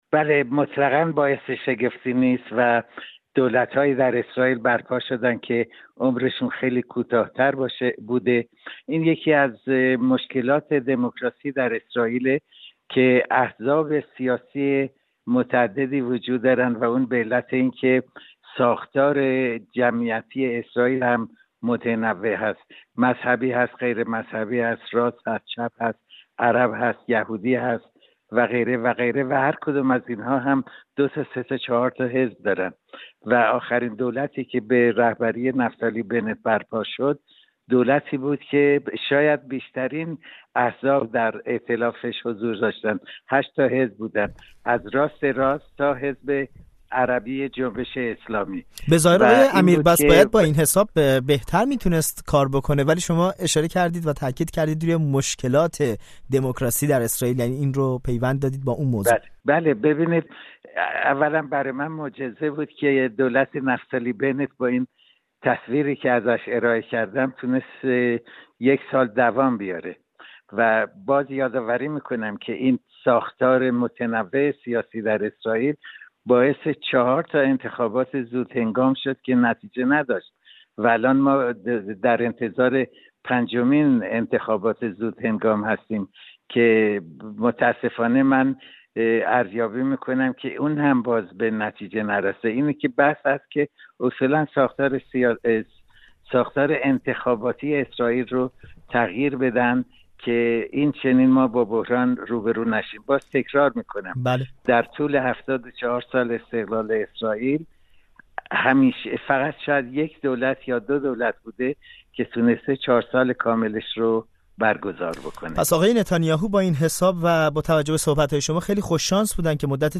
گفت و گو کرده است.